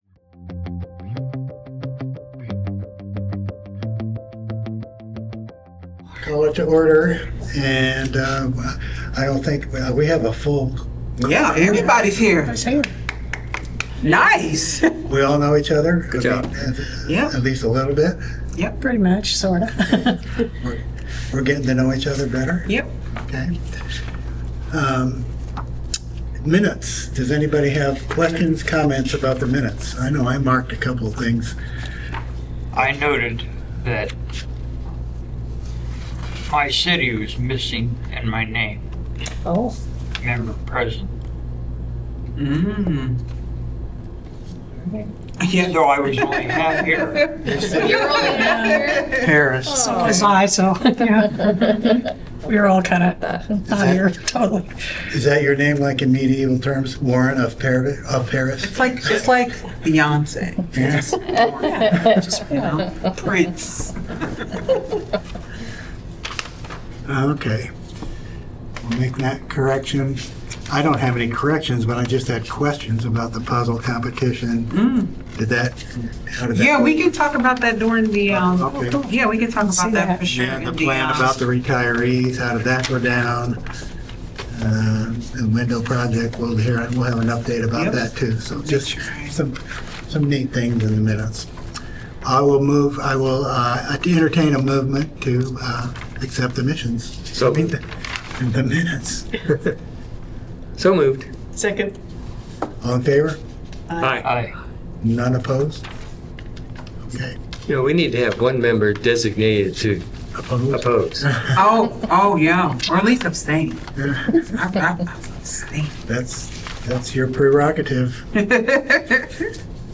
Regular monthly meeting of the Senior Center Commission.